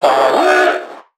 NPC_Creatures_Vocalisations_Infected [84].wav